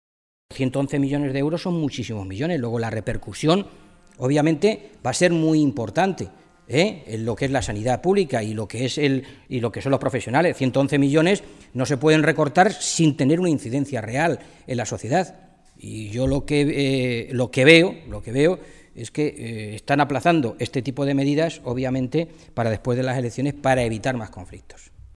Fernando Mora, portavoz de Sanidad del Grupo Parlamentario Socialista
Cortes de audio de la rueda de prensa